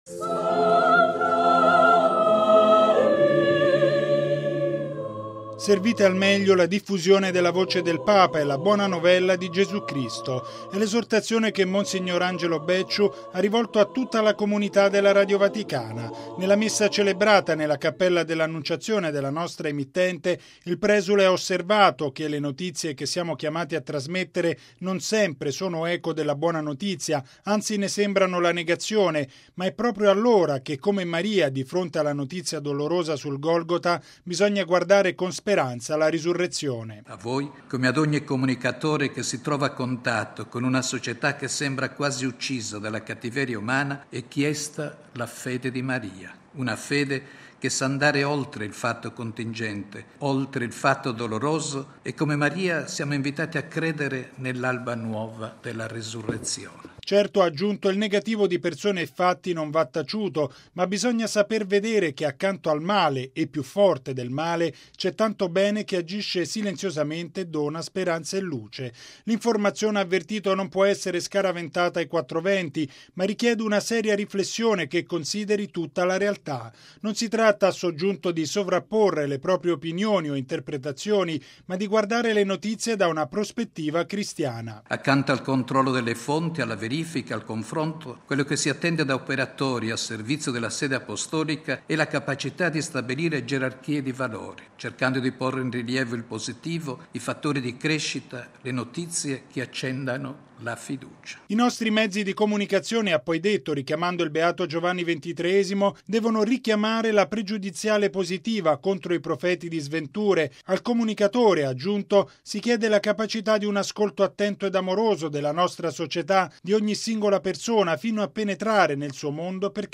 Momento culminante della ricorrenza, la Messa celebrata nella Cappella di Palazzo Pio da mons. Angelo Becciu, sostituto per gli Affari generali della Segreteria di Stato.